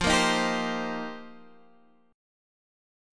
F7sus2sus4 chord